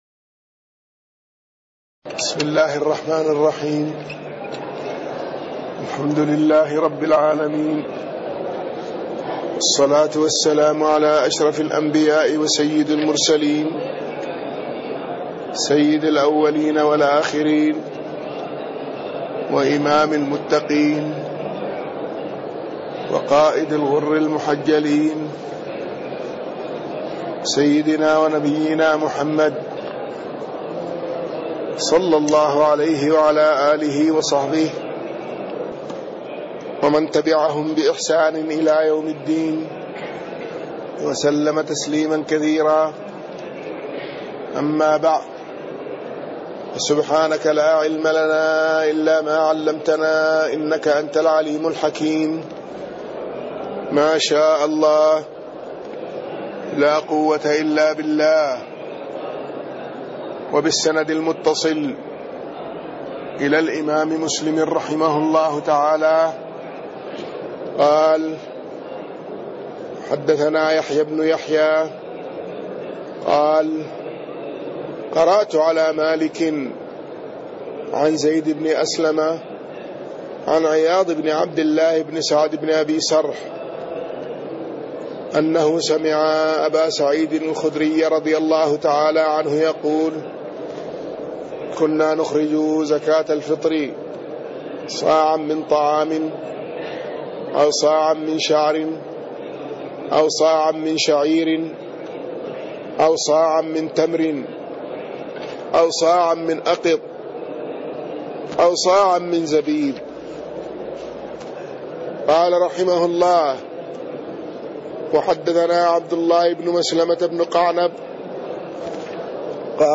تاريخ النشر ١ جمادى الأولى ١٤٣٢ هـ المكان: المسجد النبوي الشيخ